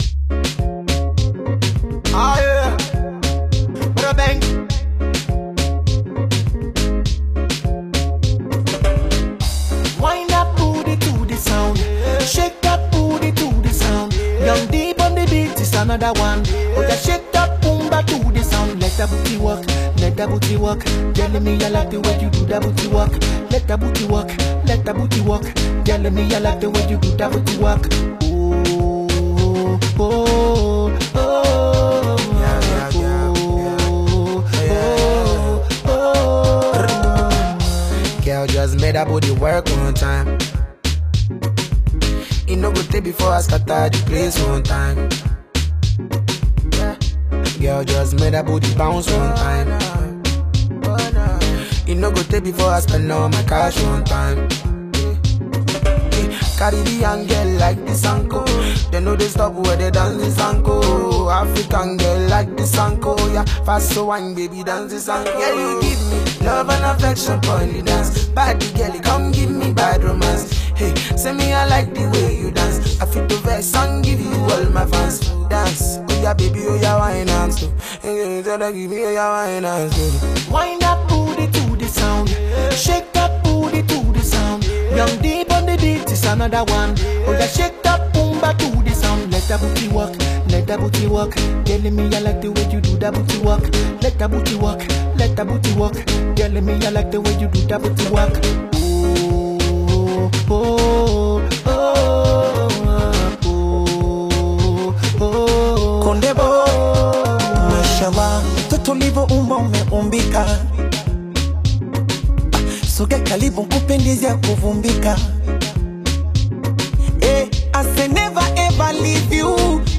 Tanzanian singer, songwriter
Nigerian singer